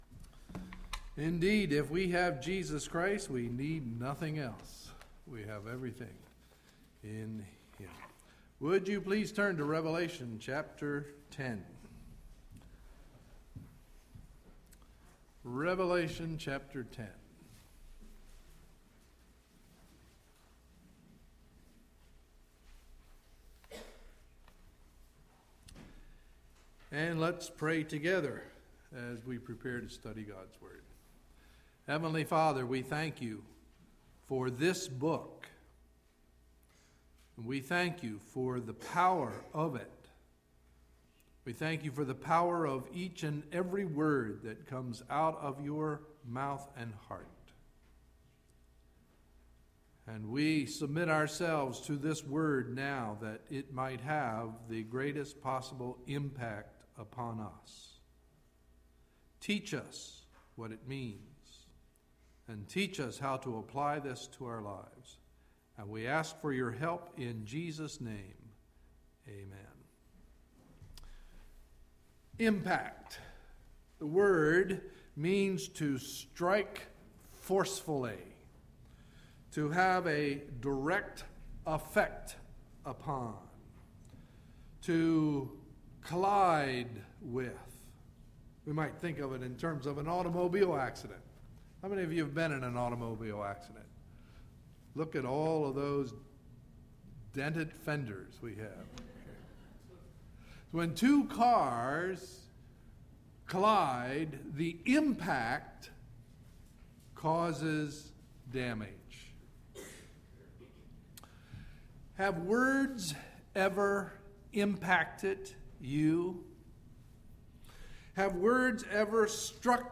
Sunday, August 21, 2011 – Morning Message